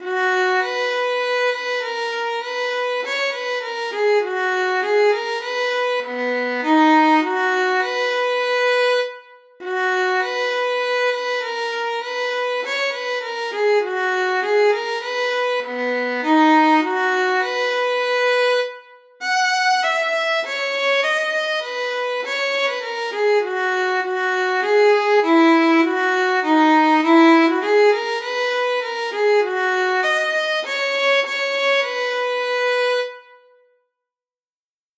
30_sackville_nobleseamans_st2__shackleyhay_major_Fiddle.mp3 (1.34 MB)
Audio fiddle of transcribed recording of stanza 2 of the Sackville ballad, sung to “Shackley Hay”